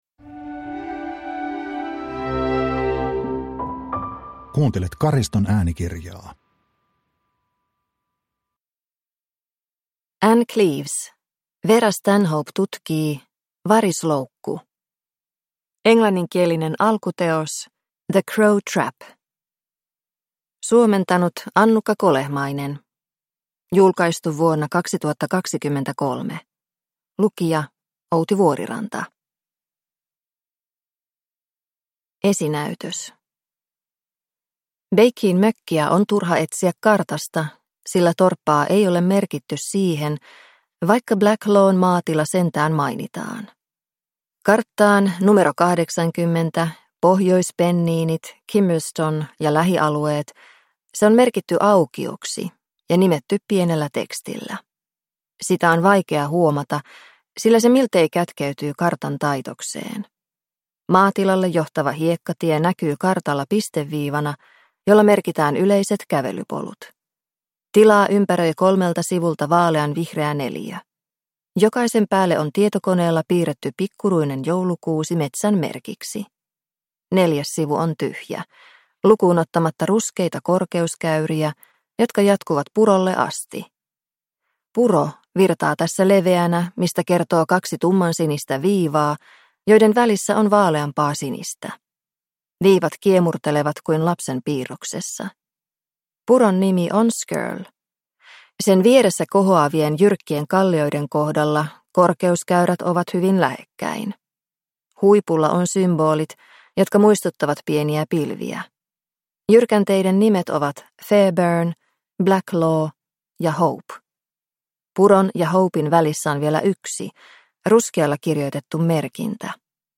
Varisloukku – Ljudbok – Laddas ner